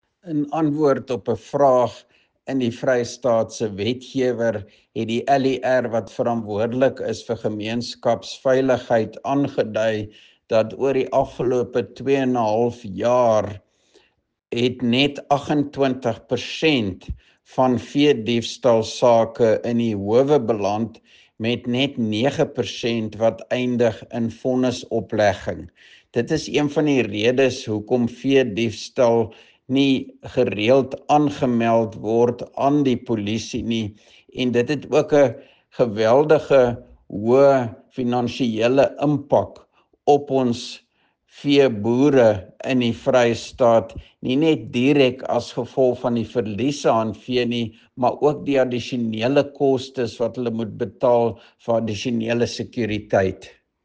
Afrikaans soundbites by Roy Jankielsohn MPL and Sesotho soundbite by Jafta Mokoena MPL.